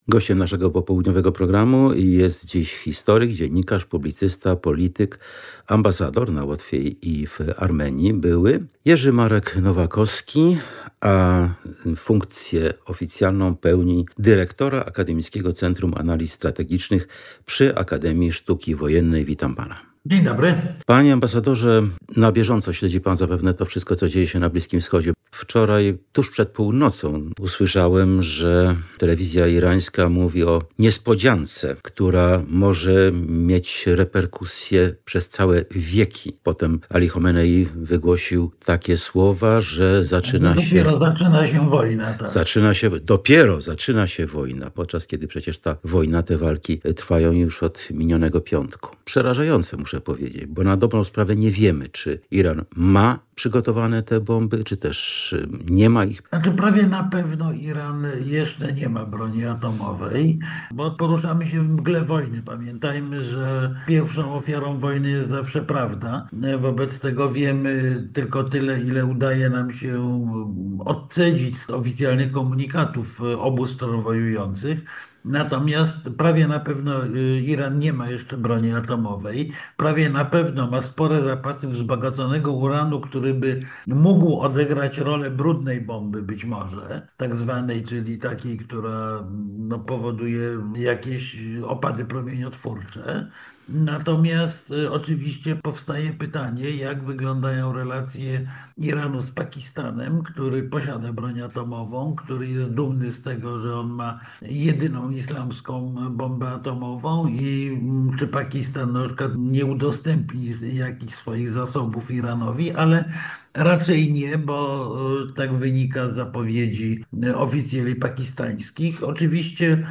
Gościem naszego południowego programu jest historyk, dziennikarz, publicysta, polityk, były ambasador na Łotwie i w Armenii Jerzy Marek Nowakowski, pełniący funkcję dyrektora Akademickiego Centrum Analiz Strategicznych przy Akademii Sztuki Wojennej.